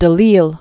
(də lēl), Claude Joseph 1760-1836.